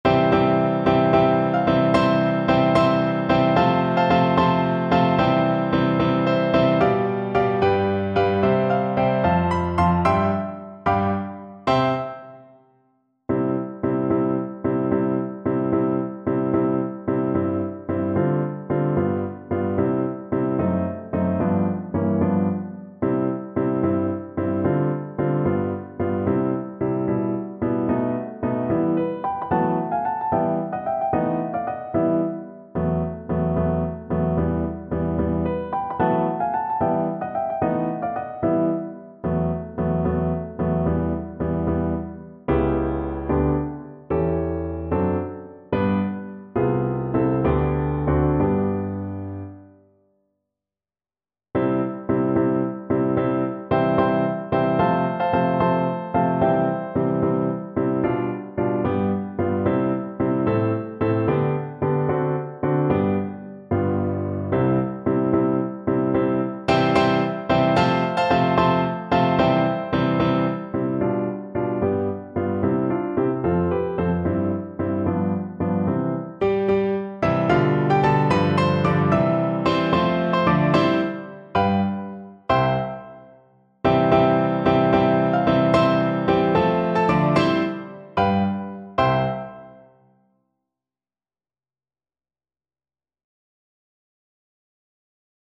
Allegro moderato .=74 (View more music marked Allegro)
6/8 (View more 6/8 Music)
Classical (View more Classical Bass Voice Music)